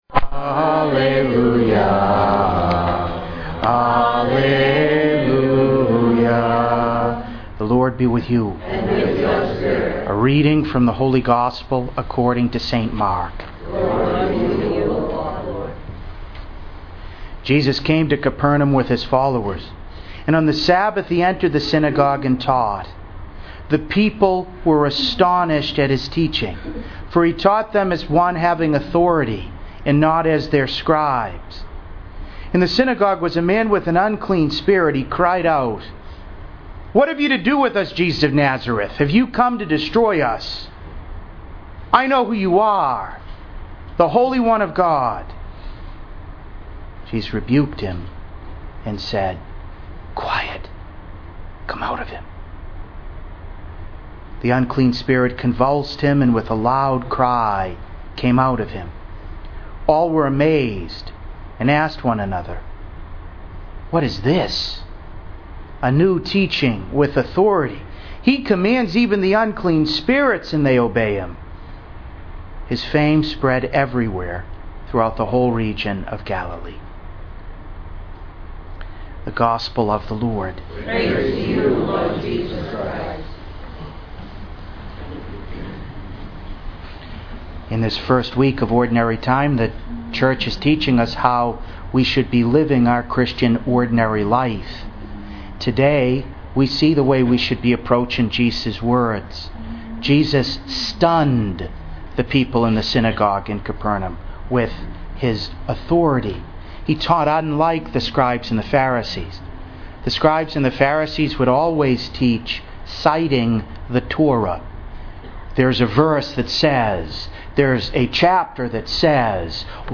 To listen to an audio recording of today’s homily, please click below: